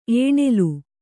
♪ ēṇelu